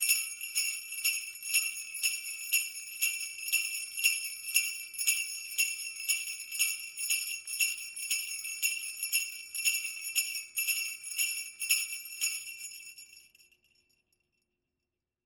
Звуки бубенцов
Звон рождественских бубенчиков